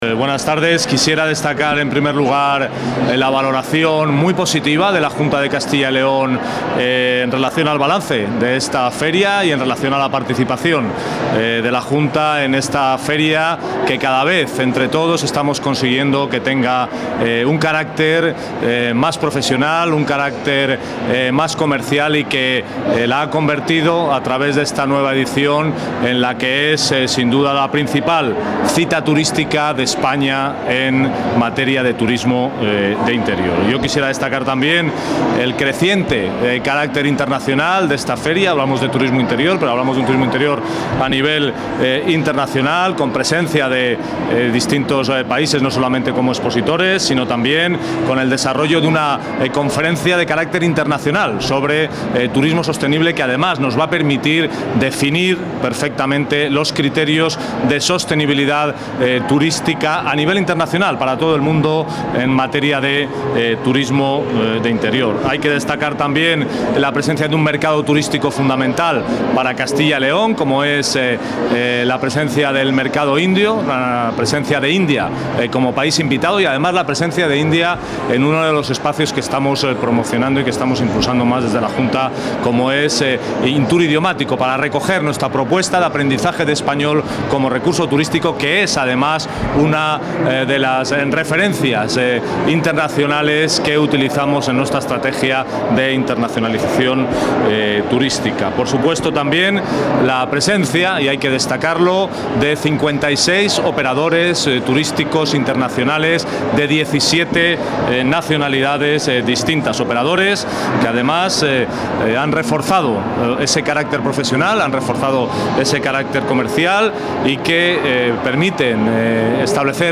Declaraciones del director general de Turismo.